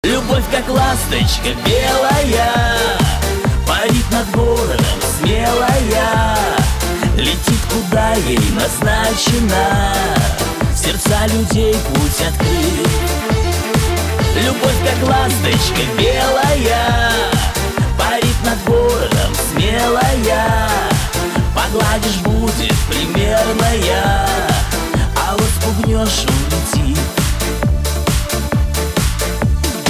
• Качество: 320, Stereo
мужской вокал
русский шансон